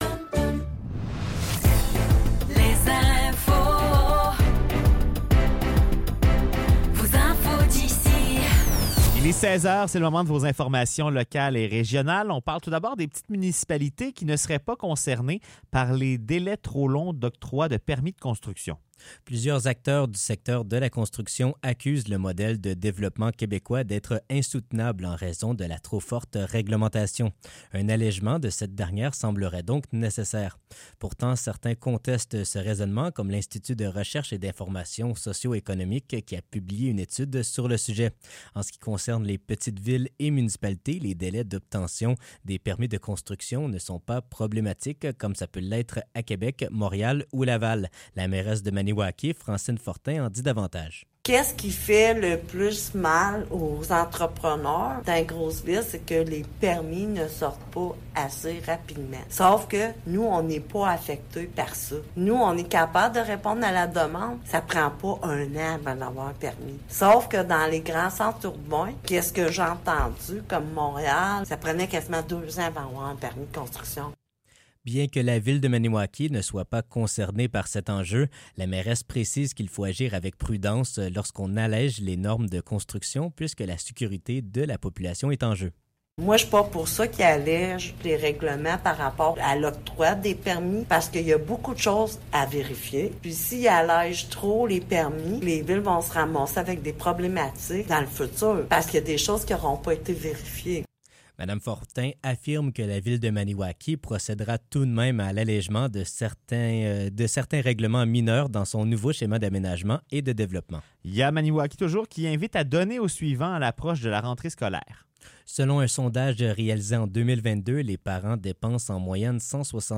Nouvelles locales - 21 Août 2024 - 16 h